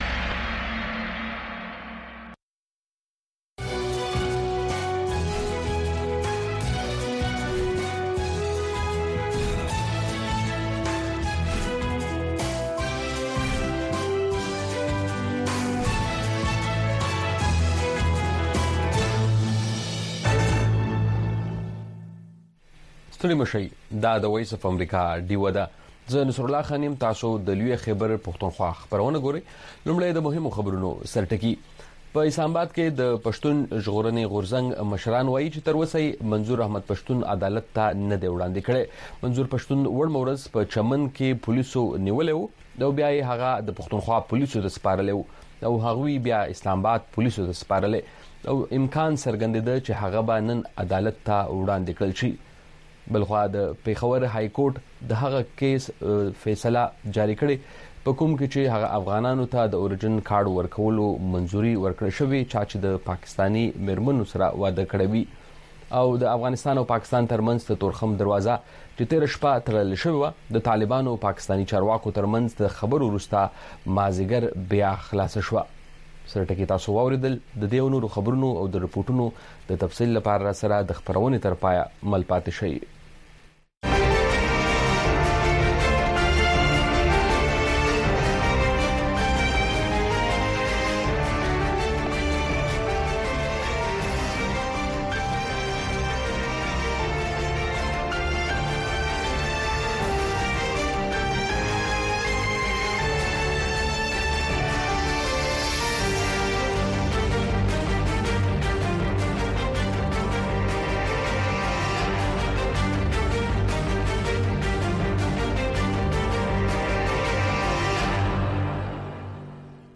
خبرونه
د وی او اې ډيوه راډيو خبرونه چالان کړئ اؤ د ورځې د مهمو تازه خبرونو سرليکونه واورئ.